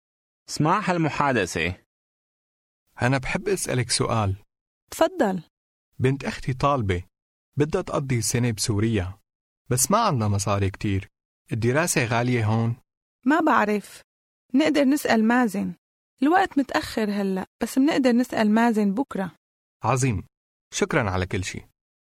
Аудио курс для самостоятельного изучения арабского языка.